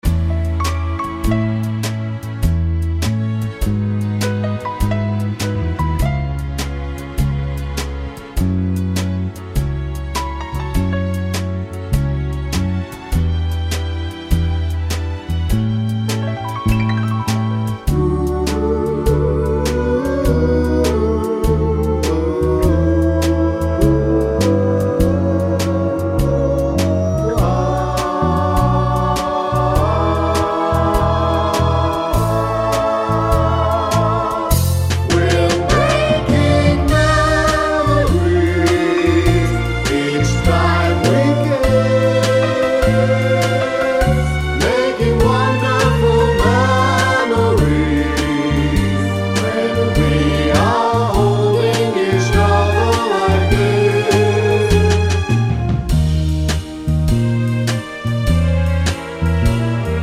Oldies (Male)